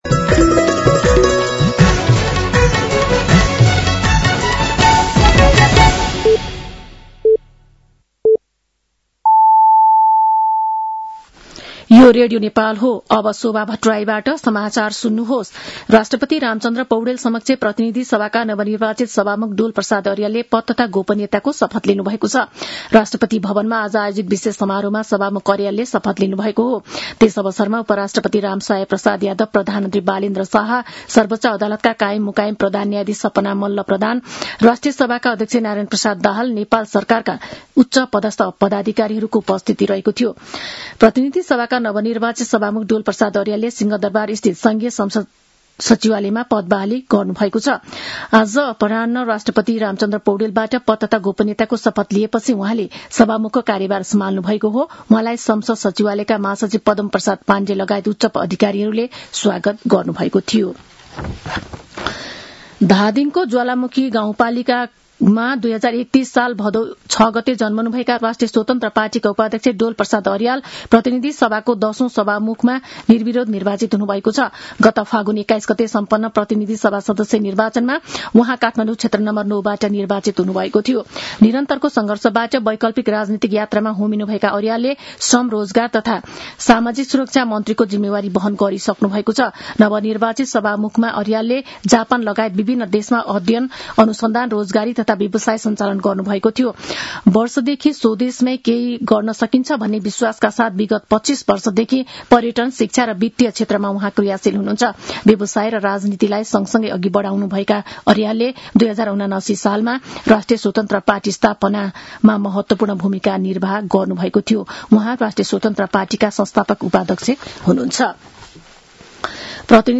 साँझ ५ बजेको नेपाली समाचार : २२ चैत , २०८२
5-pm-news-1.mp3